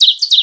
A_CHIRP.mp3